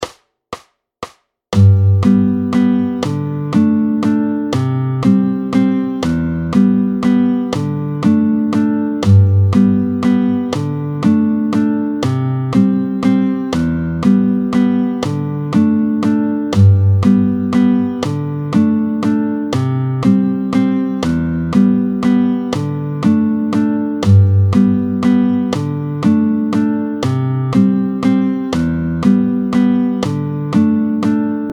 07-03 La mesure à 3/4. Vite tempo 120